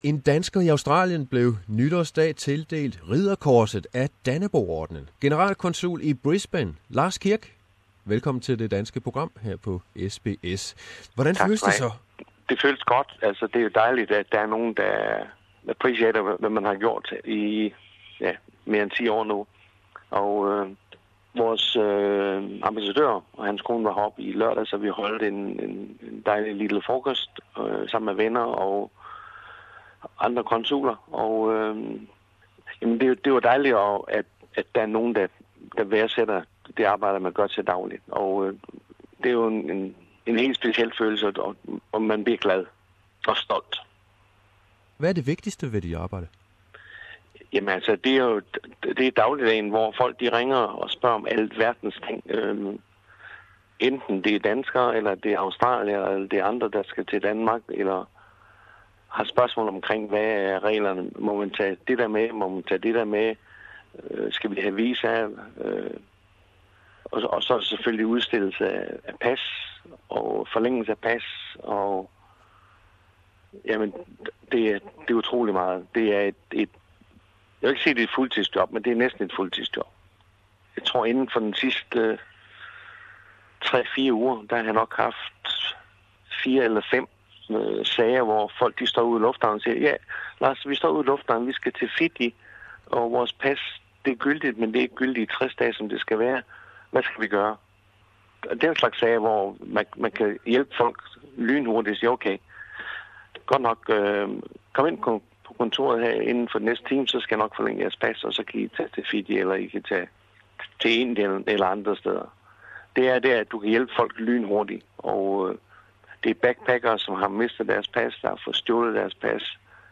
Interview with Consul General in Brisbane, Lars Kirk, who as from 1 January 2016 has been given the Knight's Cross of Dannebrog-order.